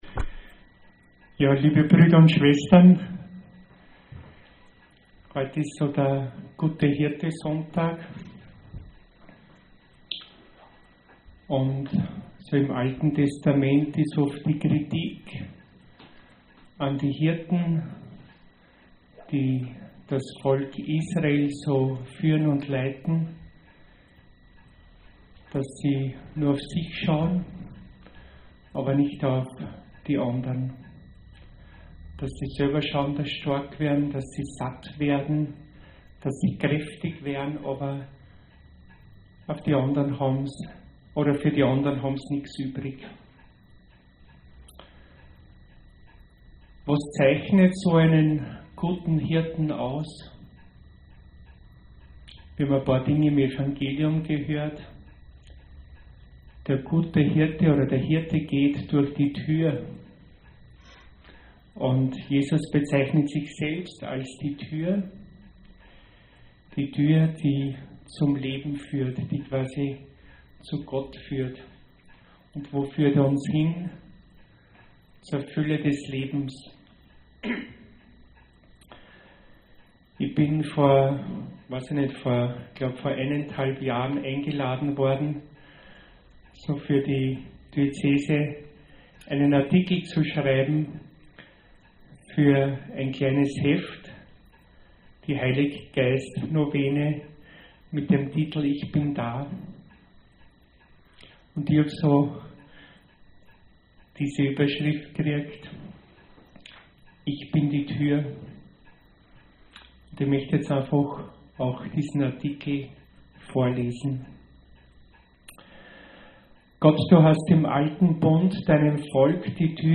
Predigt 4. Sonntag der Osterzeit